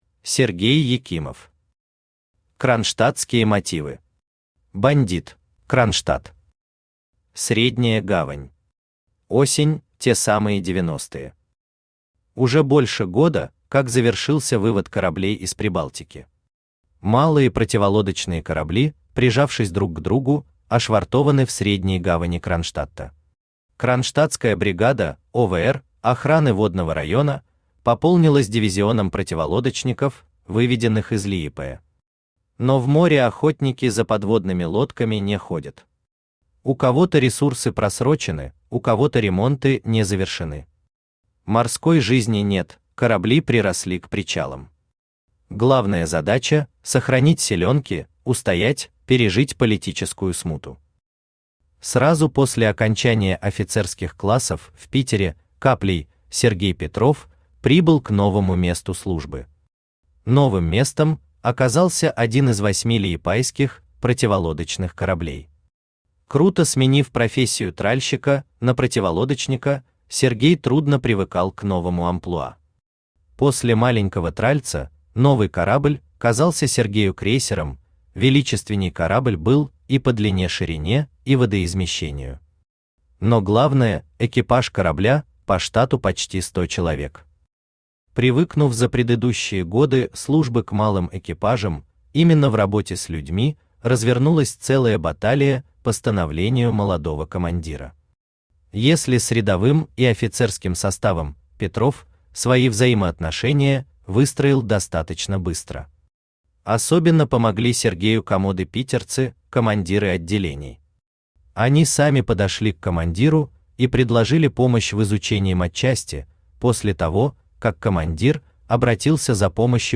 Читает: Авточтец ЛитРес